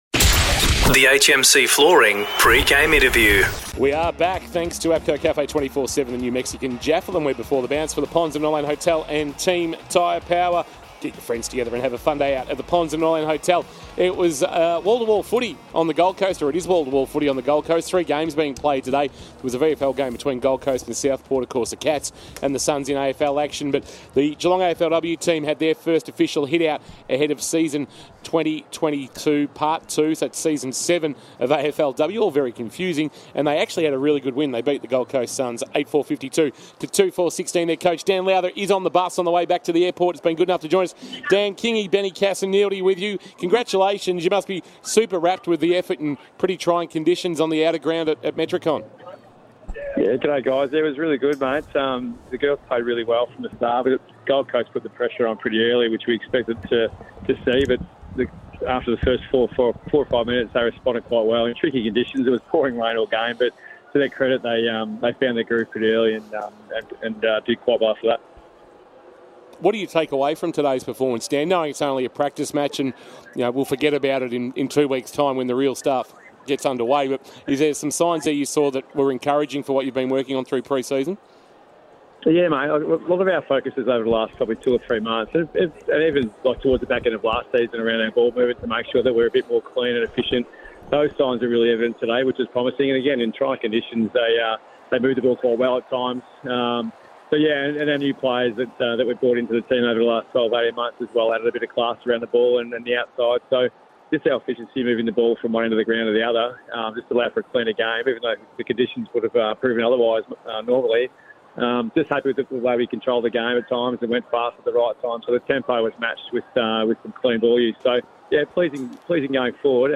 2022 – AFL ROUND 22 – GOLD COAST vs. GEELONG: Pre-match Interview